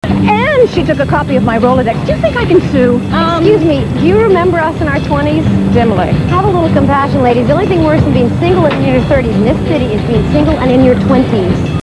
Rock
Comment: surf